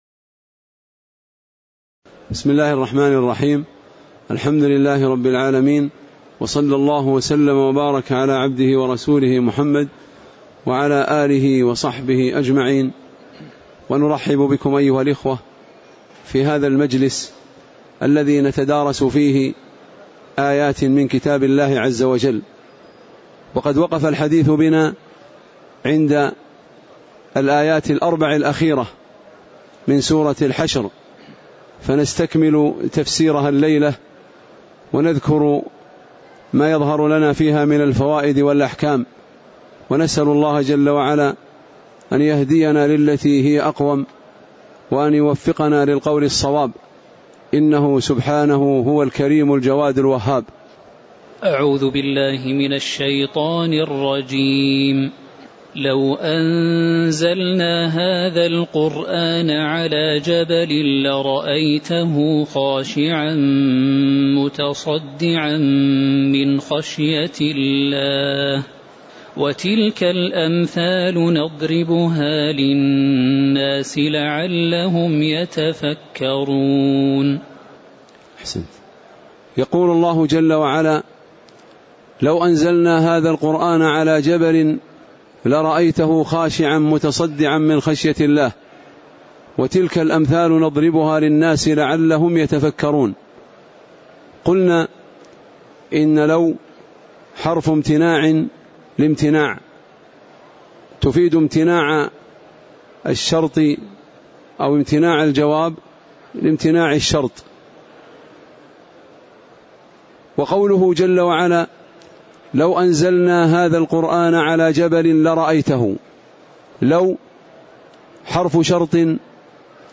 تاريخ النشر ٨ رجب ١٤٣٨ هـ المكان: المسجد النبوي الشيخ